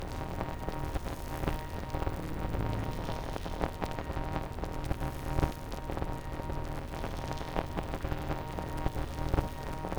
pgs/Assets/Audio/Sci-Fi Sounds/Hum and Ambience/Hum Loop 8.wav at master
Hum Loop 8.wav